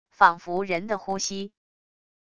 仿佛人的呼吸wav音频